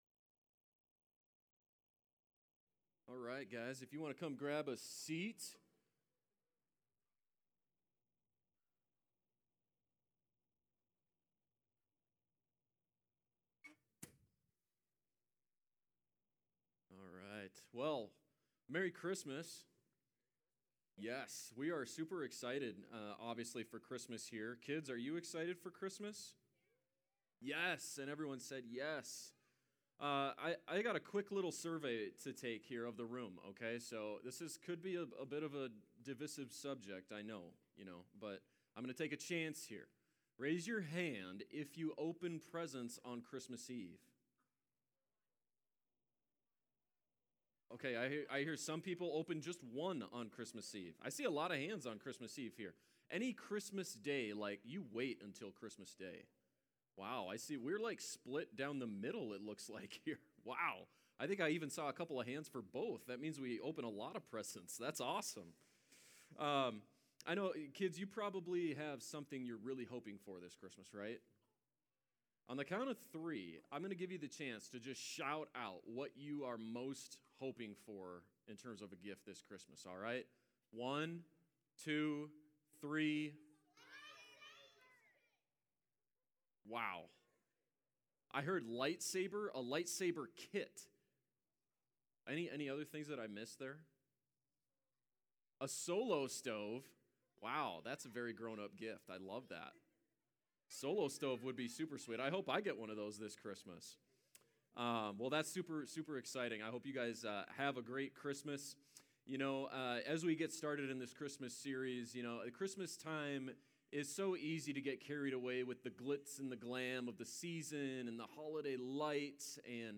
Christmas can highlight the heaviness in our hearts. In this sermon, we look to Isaiah 9 for three reasons why Jesus is our light in dark times.